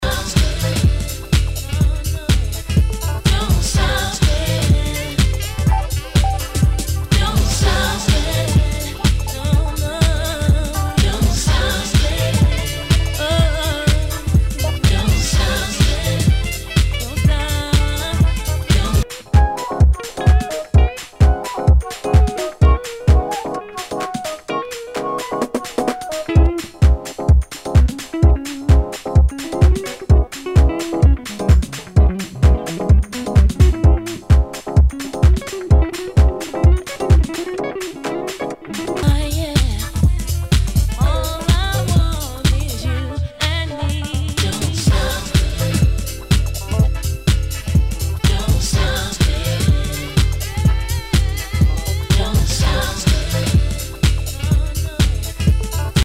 HOUSE/TECHNO/ELECTRO
ナイス！ディープ・ハウス！